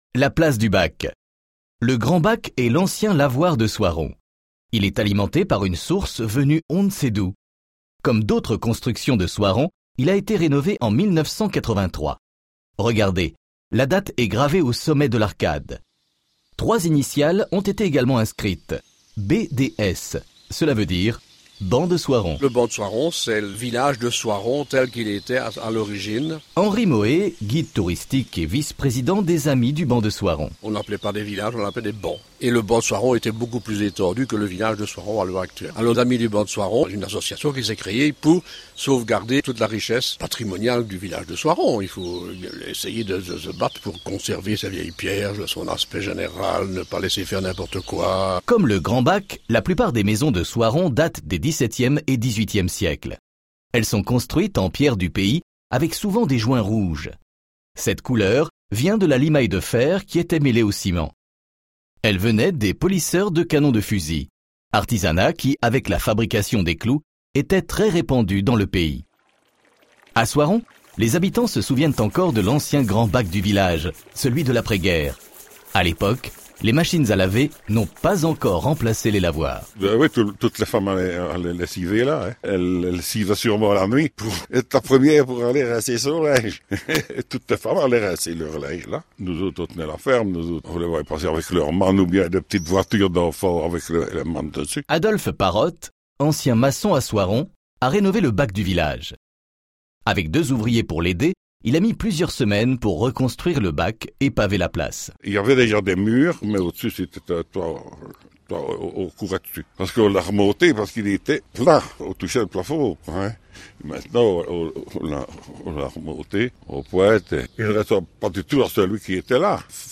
Audioguide - Soiron - Plus Beaux Villages de Wallonie